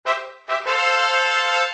trumpet_fanfare.ogg